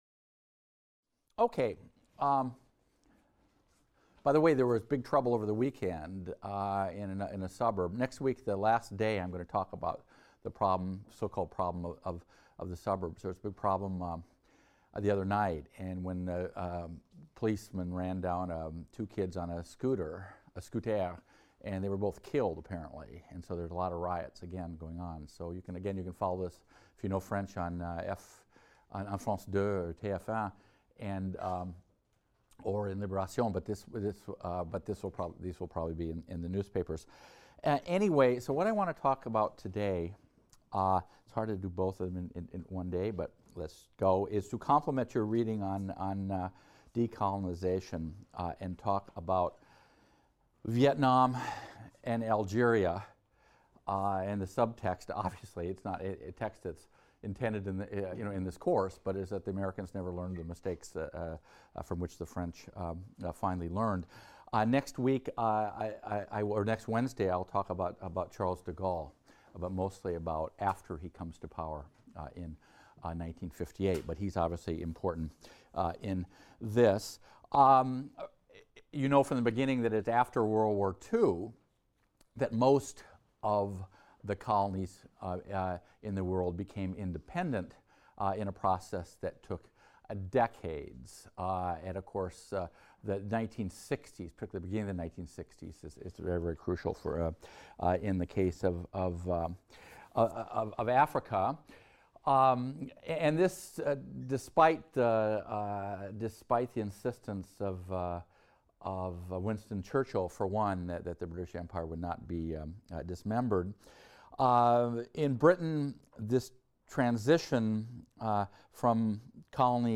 HIST 276 - Lecture 21 - Vietnam and Algeria | Open Yale Courses